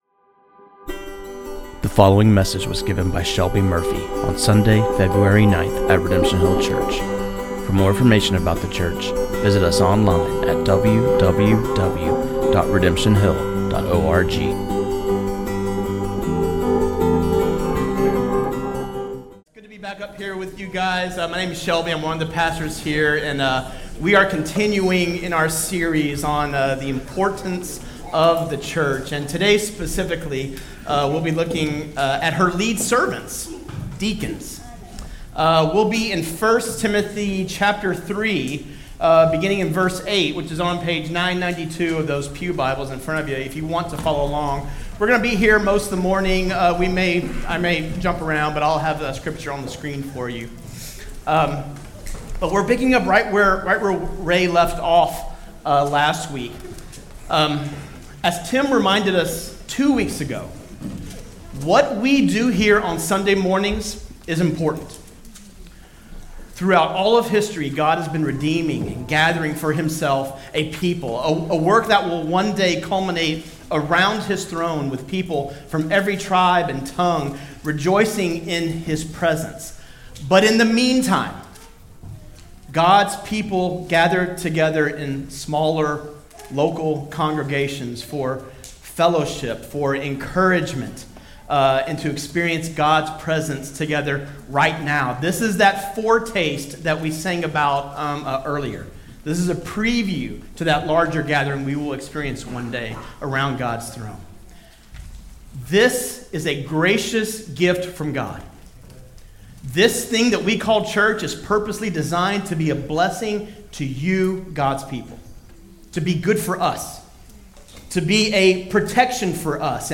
This sermon on 1 Timothy 3:8-13